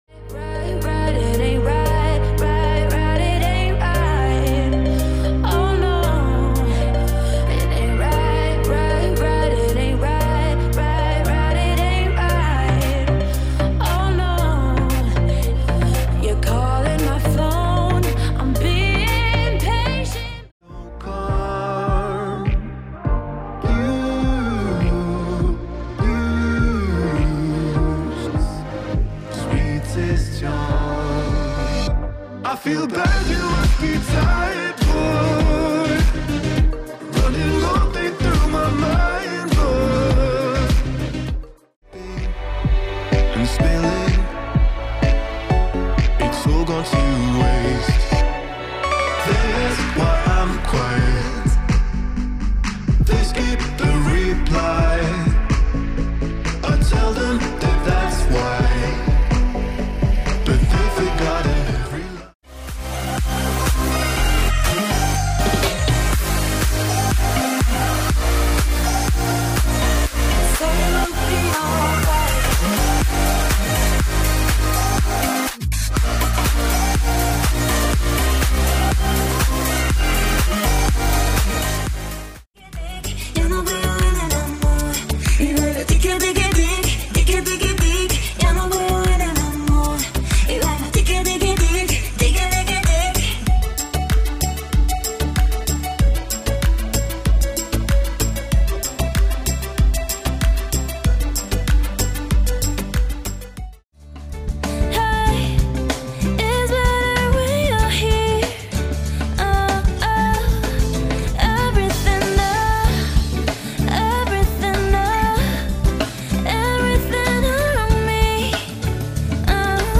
styl - pop